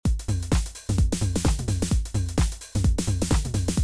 Now for the fun part. We have edited C, and in this audio clip below, we’ve looped it for two bars for reference purposes:
For the HAND CLAP, we programmed it to play on steps 5 and 13, the 2nd and 4th beats of the bar.
We kept the WEAK setting toggled on and programmed a HIGH TOM, a MID TOM at the end of the bar.
We turned off WEAK for that as we wanted the LOW TOM to be powerful.